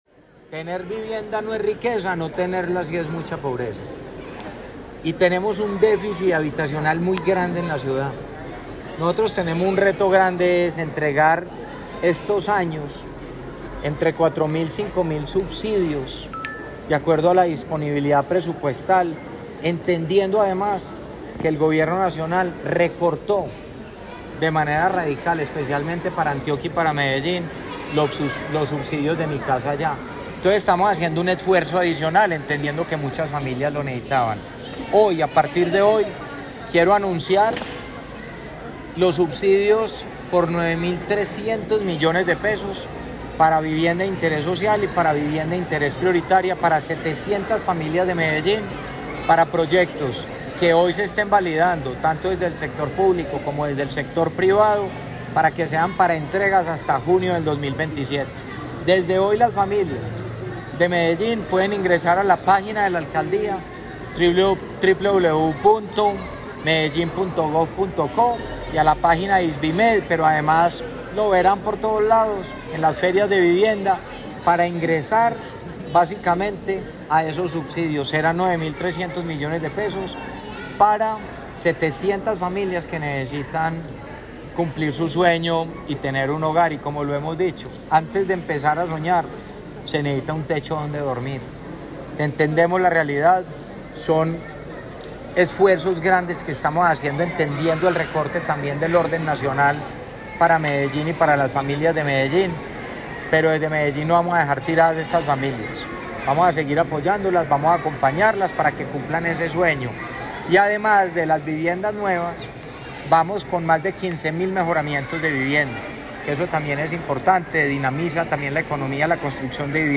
Declaraciones Federico Gutiérrez (Compra tu casa)
Declaraciones-Federico-Gutierrez-Compra-tu-casa.mp3